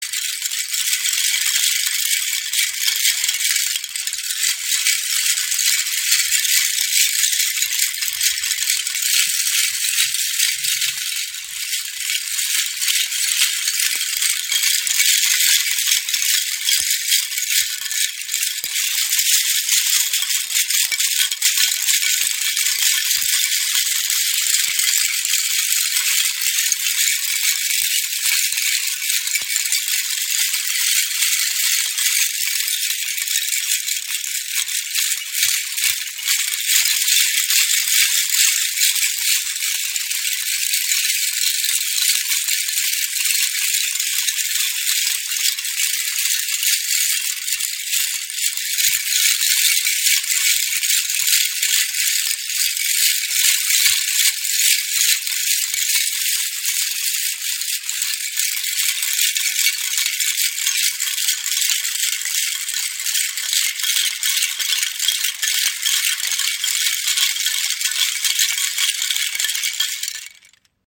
Rassel | XL Hand-Shaker | Abalone Muscheln im Raven-Spirit WebShop • Raven Spirit
Klangbeispiel
Extra grosser Shaker aus kleinen Abalone Muschelschalen.
Klarer Sound der sanft aber auch kräftig eingesetzt werden kann.